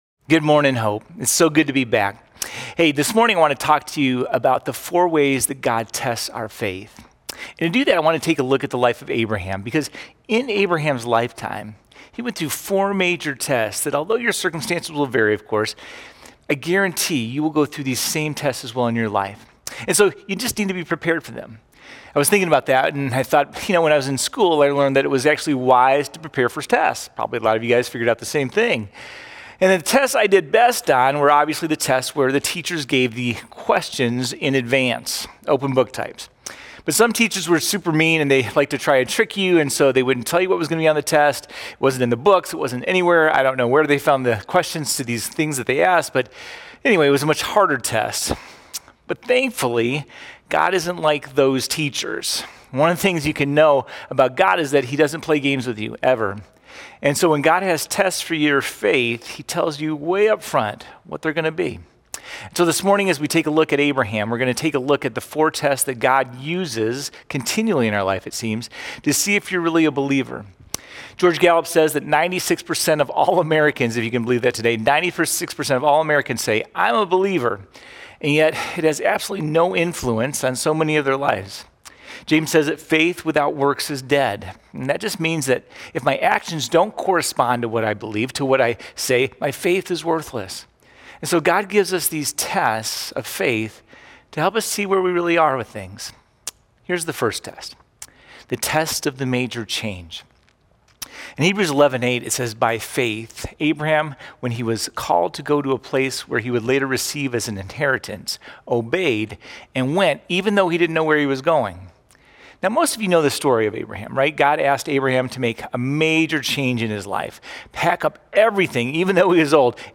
07-13-Sermon.mp3